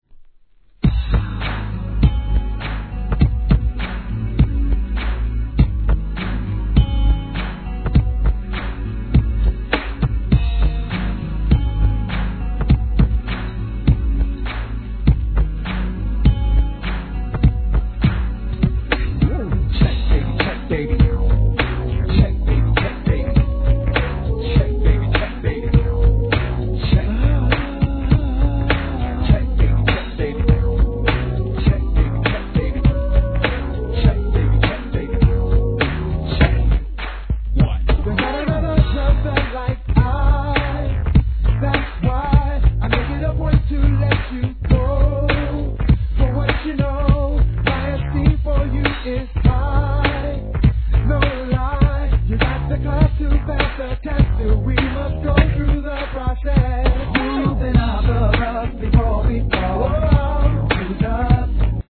HIP HOP/R&B
1994年、NEW JACK SWINGバリに跳ねたBEATが乗れます!